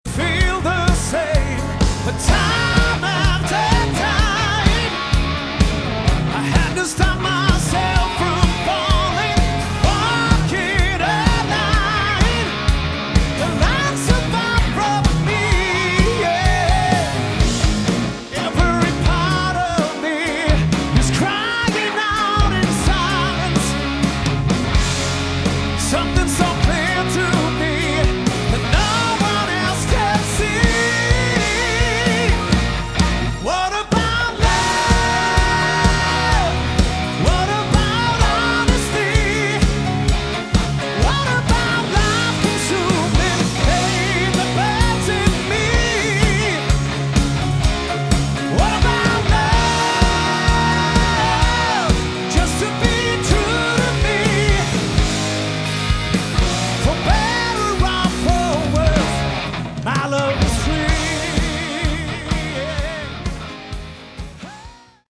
Category: Melodic Rock
lead vocals
guitar
keyboards
drums
bass